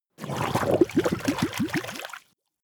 Gemafreie Sounds: Wasser - Blubbern